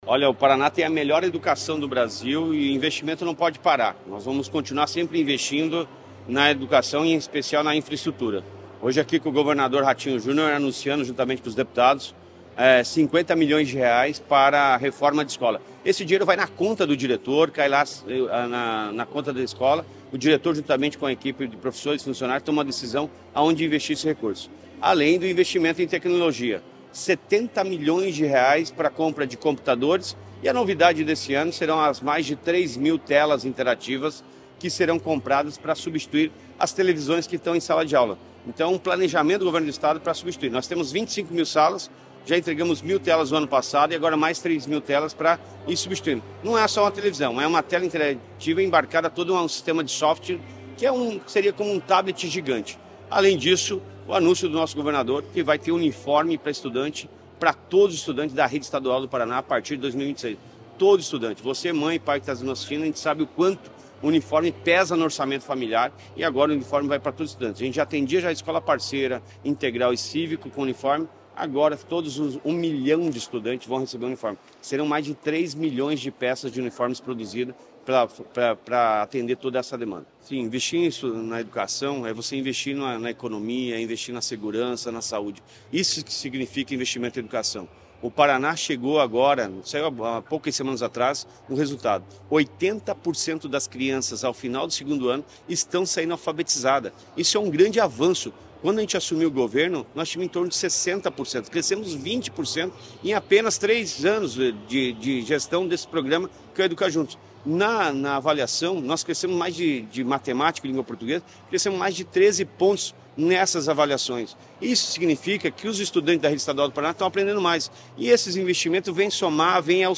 Sonora do secretário da Educação, Roni Miranda, obre o pacote de investimentos e uniformes para toda a rede estadual